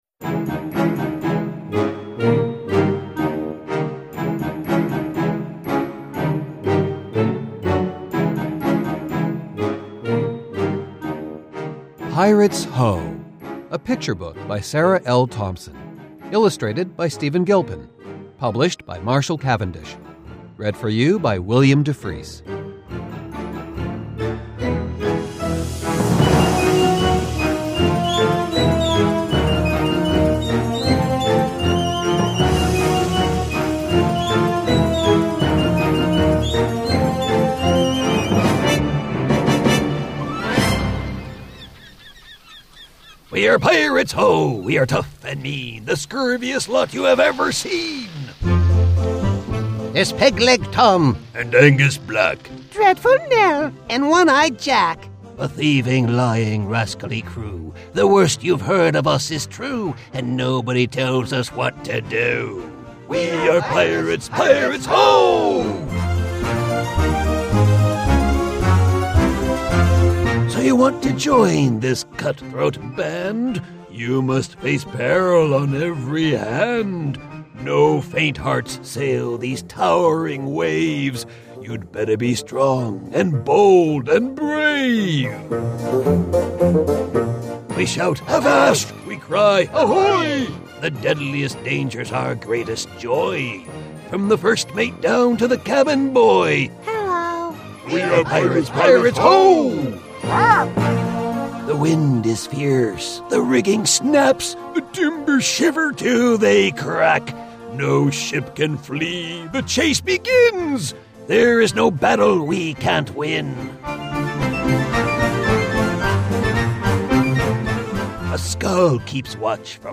Category: Picture Book